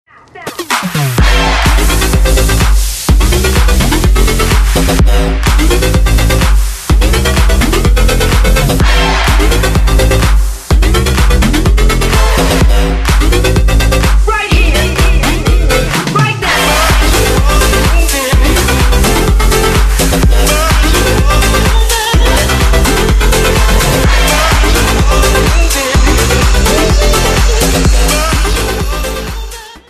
• Качество: 128, Stereo
громкие
мощные
dance
future house
club
качающие